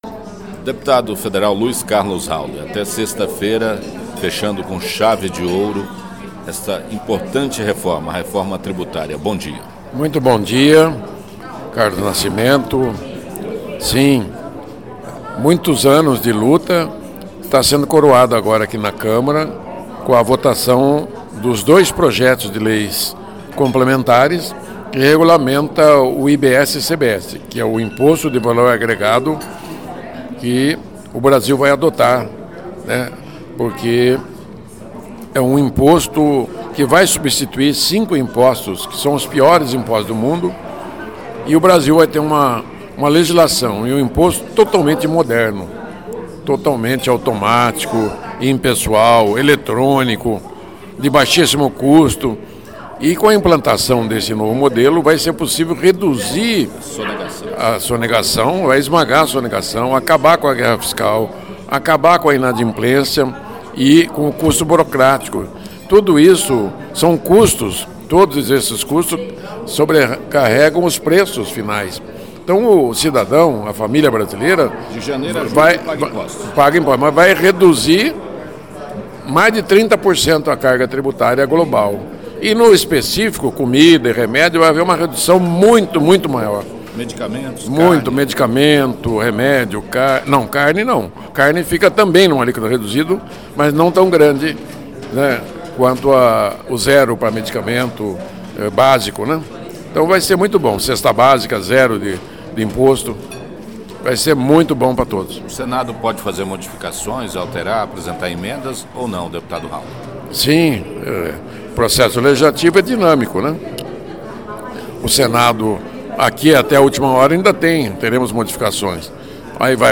direto de Brasília, conversou com o dep. Hauly (Podemos), um dos parlamentares que mais lutou pela proposta.